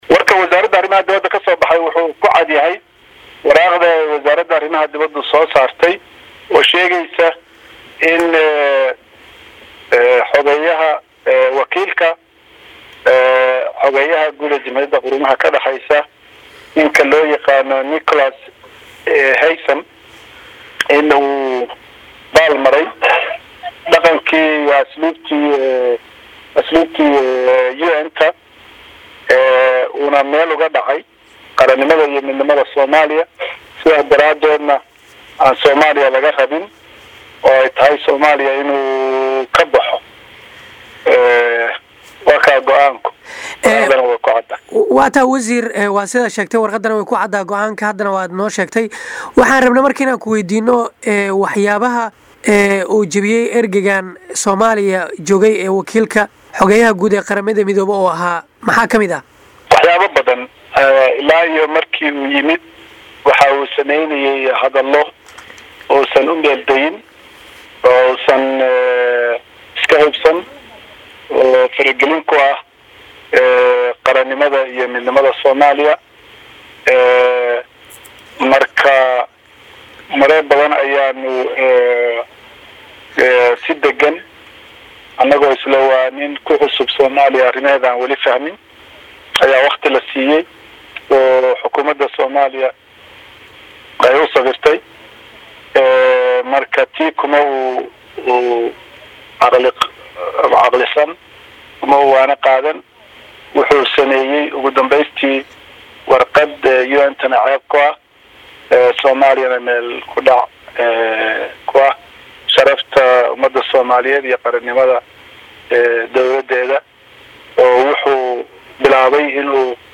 Wareysiyada